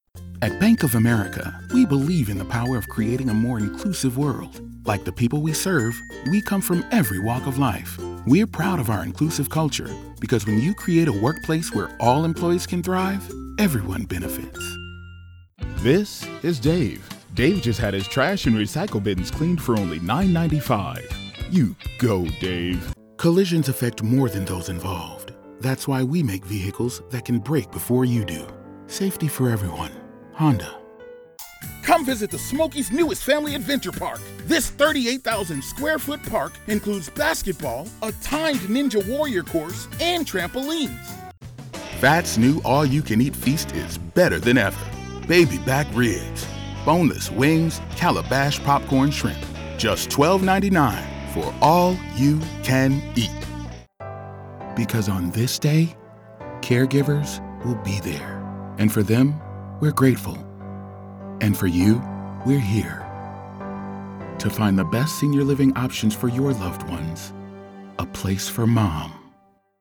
Adult
Has Own Studio
black us
southern us
standard us
commercial